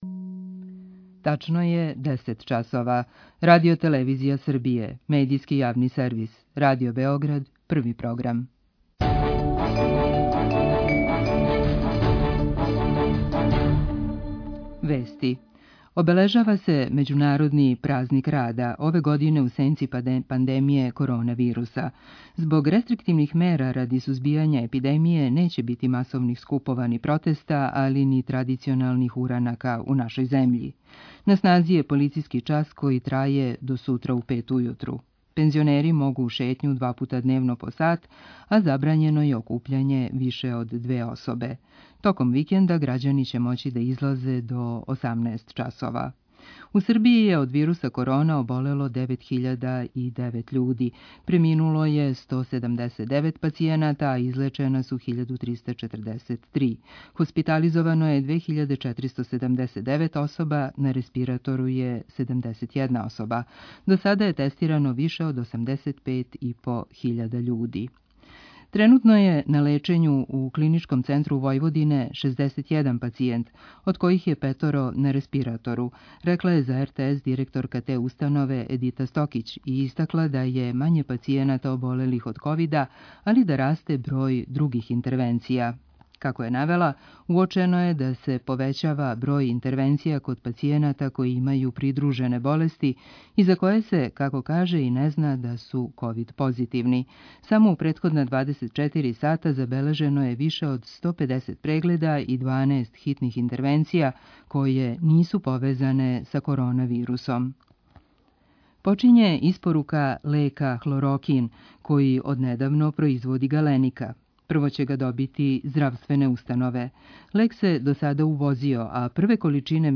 Чућемо како ће Влада Србије помоћи привреди и грађанима којима је због епидемије корона вируса помоћ неопходна. Наш саговорник биће министар финансија Синиша Мали.